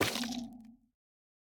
Minecraft Version Minecraft Version latest Latest Release | Latest Snapshot latest / assets / minecraft / sounds / block / sculk_vein / break5.ogg Compare With Compare With Latest Release | Latest Snapshot
break5.ogg